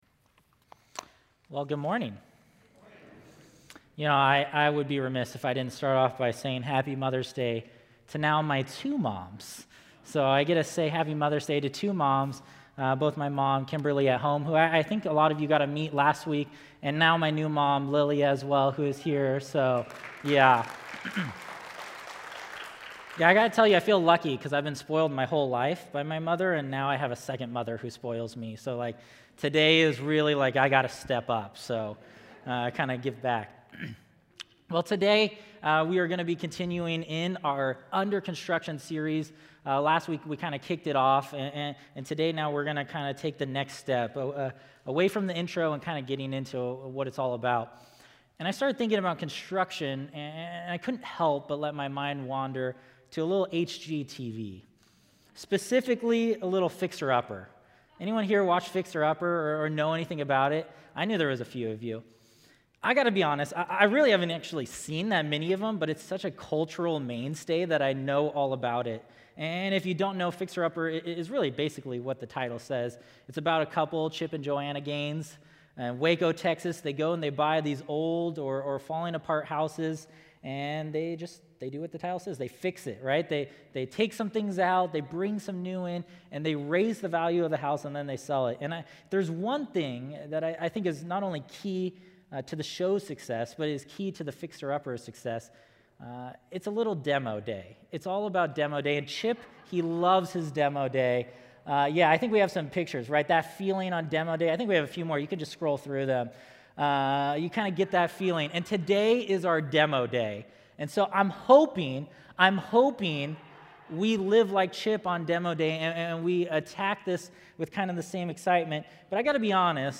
A message from the series "Under Construction."